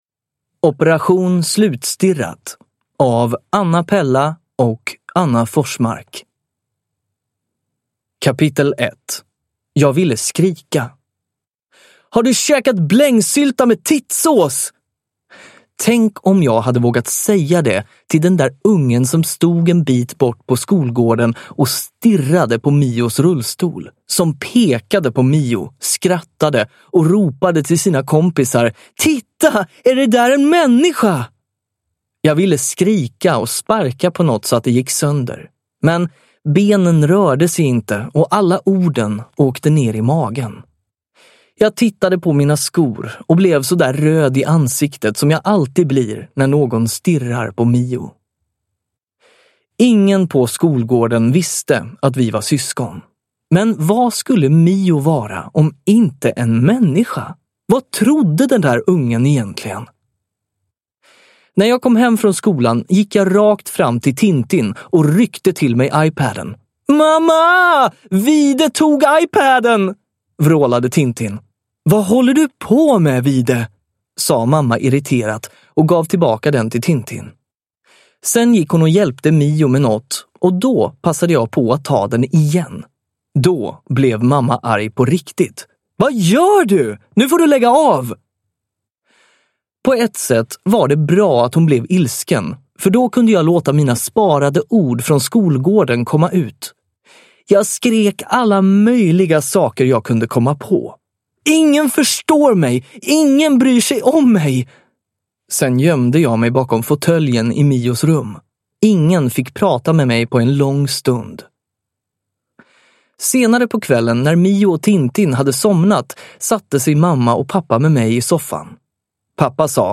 Operation slutstirrat – Ljudbok – Laddas ner